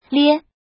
怎么读
[ liē ]
lie1.mp3